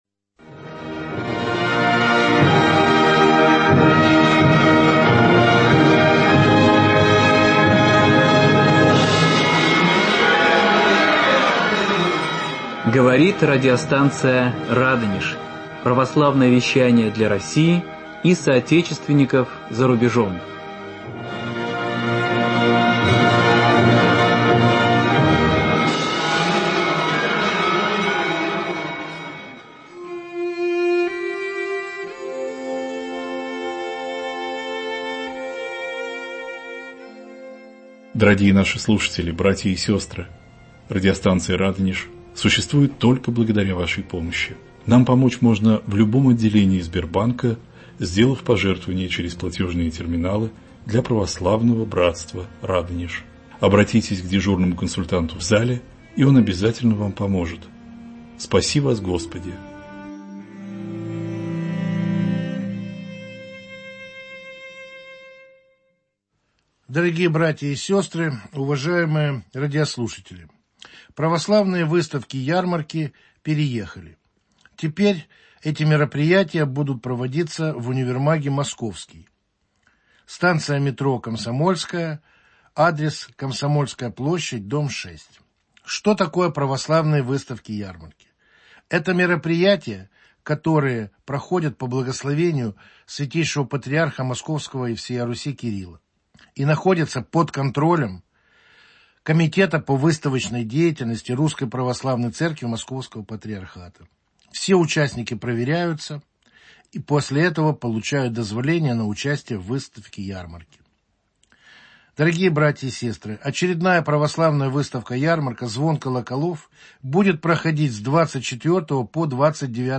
ответы на вопросы радиослушателей. Повтор эфира.